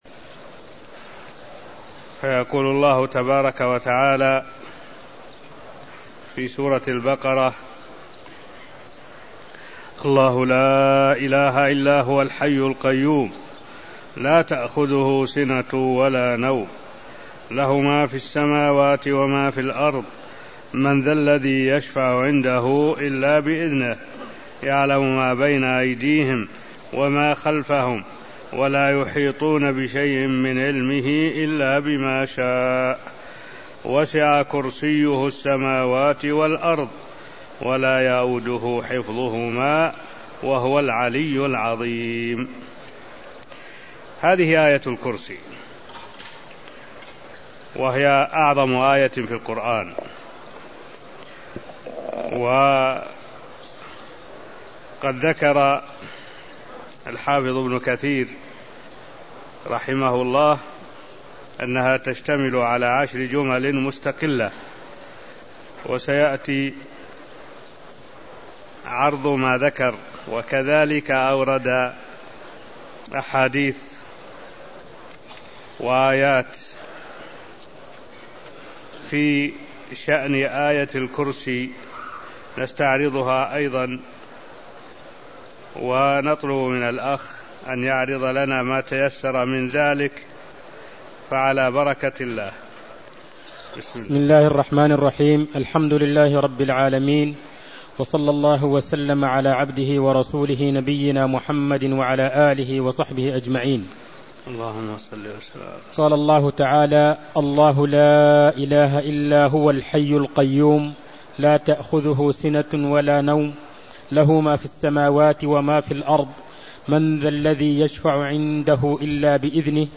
المكان: المسجد النبوي الشيخ: معالي الشيخ الدكتور صالح بن عبد الله العبود معالي الشيخ الدكتور صالح بن عبد الله العبود تفسير آية الكرسي من سورة البقرة (0124)